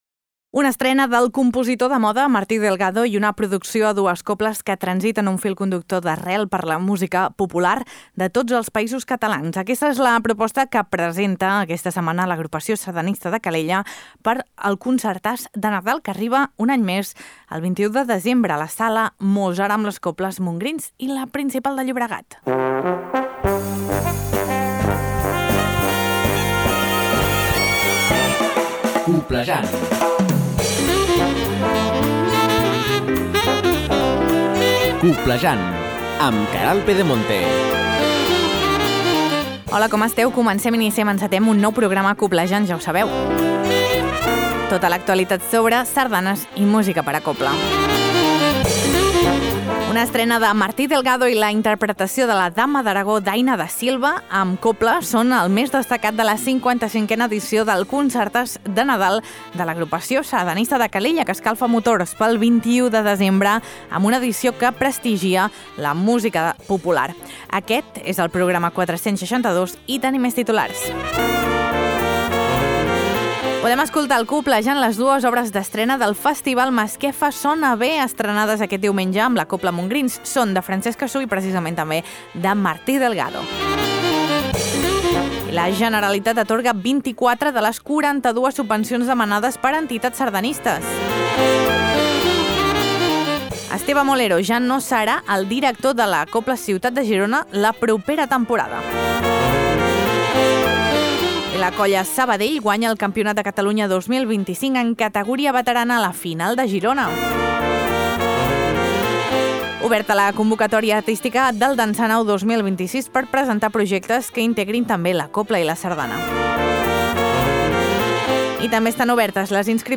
I podem escoltar al Coblejant les dues obres d’estrena del Festival Masquefa Sona Bé estrenades aquest diumenge amb la cobla Montgrins: són de Francesc Cassú i Martí Delgado! A les Notícies, la Generalitat atorga 24 de les 42 subvencions demanades per entitats sardanistes.